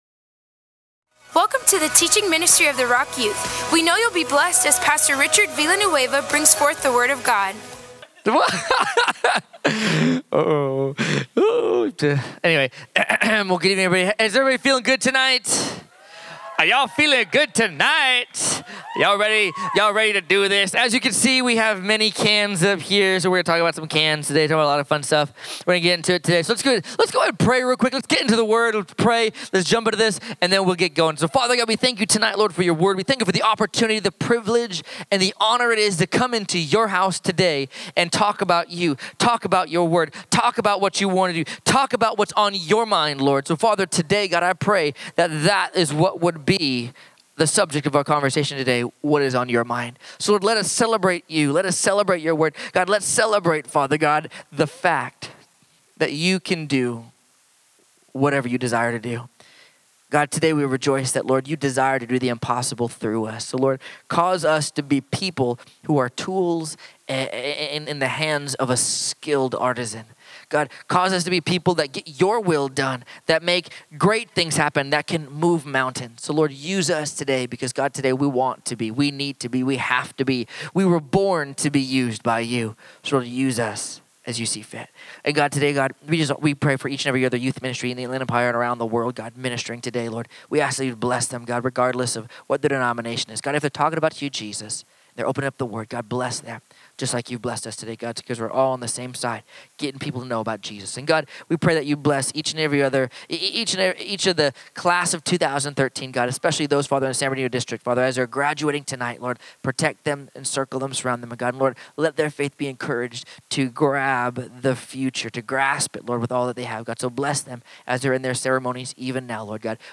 FFOF_Panel_5_22_13.mp3